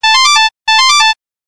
Beep